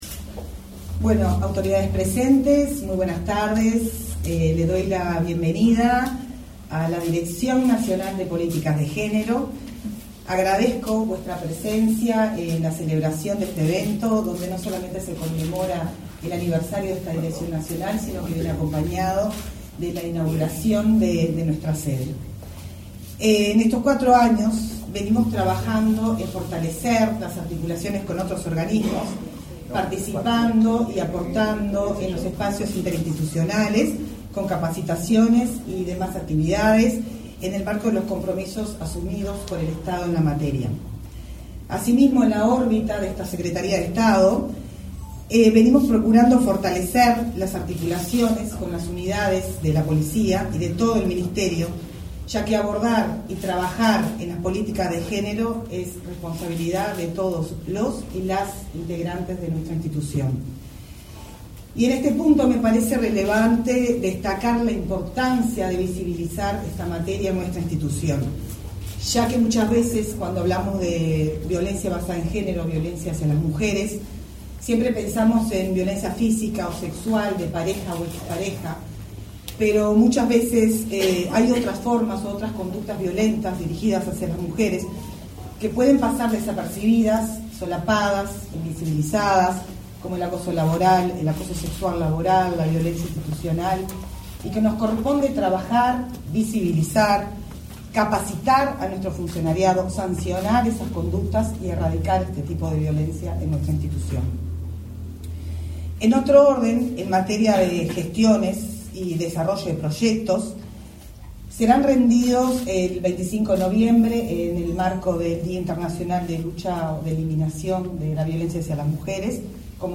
Acto por la inauguración de sede de la Dirección Nacional de Políticas de Género
Acto por la inauguración de sede de la Dirección Nacional de Políticas de Género 15/08/2024 Compartir Facebook X Copiar enlace WhatsApp LinkedIn El Ministerio del Interior presentó, este 15 de agosto, la nueva sede de la Dirección Nacional de Políticas de Género, en el marco de su cuarto aniversario. En el evento disertaron el subsecretario del Ministerio del Interior, Pablo Abdala, y la titular de la dirección, Angelina Ferreira.